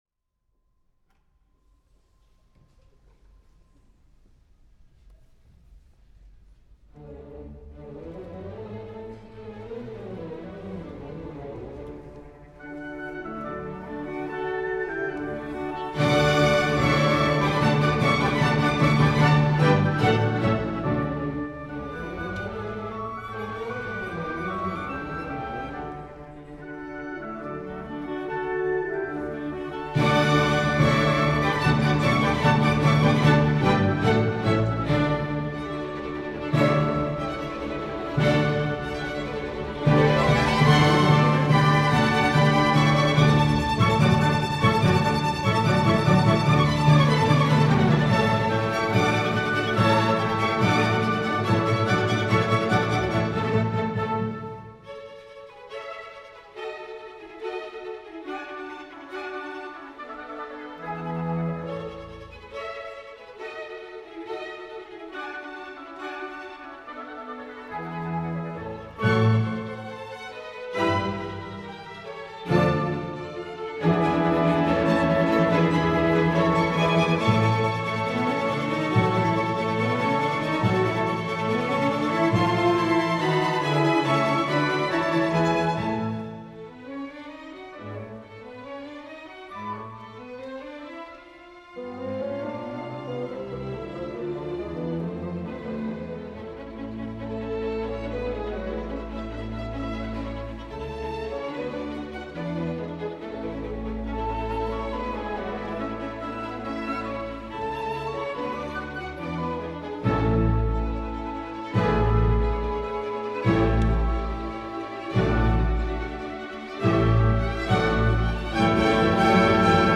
Mozart - The Marriage of Figaro - Overture